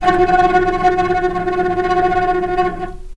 healing-soundscapes/Sound Banks/HSS_OP_Pack/Strings/cello/tremolo/vc_trm-F#4-pp.aif at 01ef1558cb71fd5ac0c09b723e26d76a8e1b755c
vc_trm-F#4-pp.aif